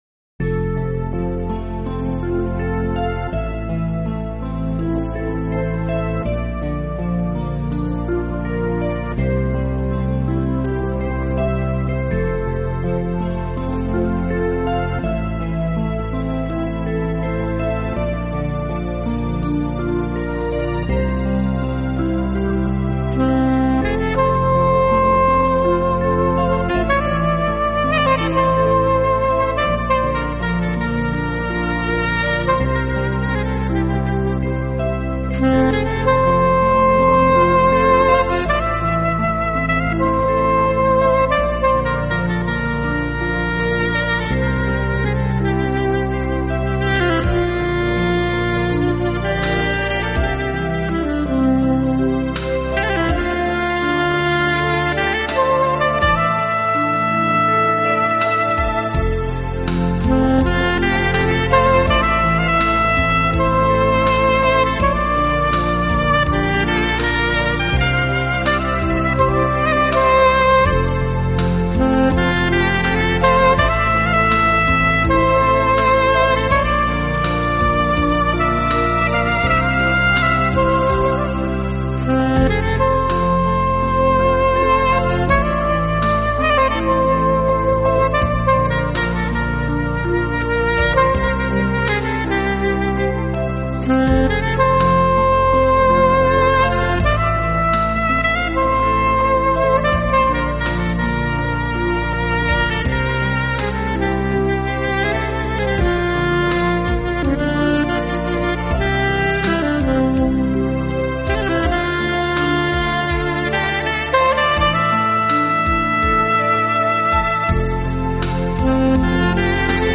【经典萨克斯】